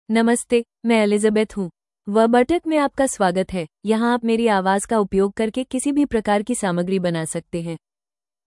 ElizabethFemale Hindi AI voice
Elizabeth is a female AI voice for Hindi (India).
Voice sample
Listen to Elizabeth's female Hindi voice.
Female
Elizabeth delivers clear pronunciation with authentic India Hindi intonation, making your content sound professionally produced.